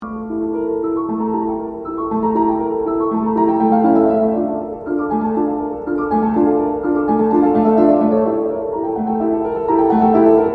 and now this motive has turned into a rage, played again and again, with more and more urgency: